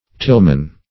Tillman \Till"man\, n.; pl. Tillmen.